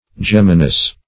geminous - definition of geminous - synonyms, pronunciation, spelling from Free Dictionary Search Result for " geminous" : The Collaborative International Dictionary of English v.0.48: Geminous \Gem"i*nous\, a. [L. geminus.] Double; in pairs.